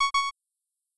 lowhealth.wav